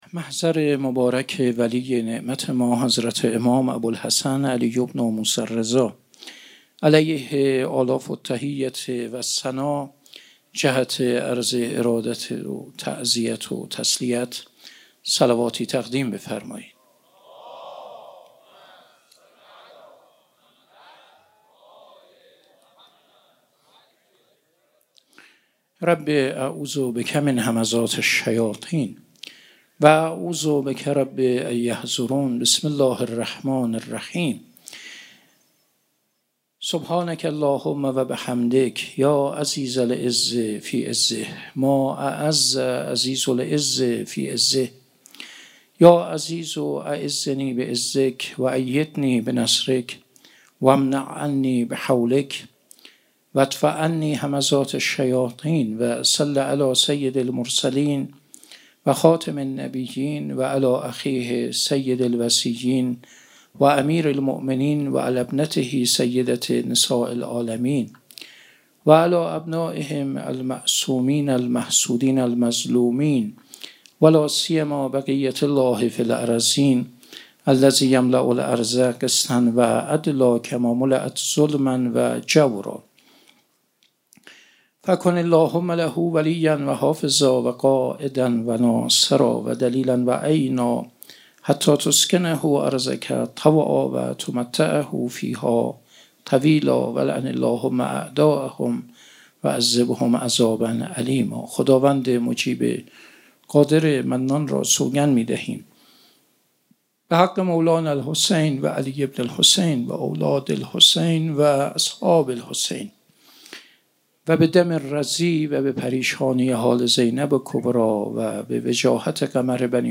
0 0 محرم1401 - سخنرانی